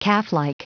Prononciation du mot calflike en anglais (fichier audio)
Prononciation du mot : calflike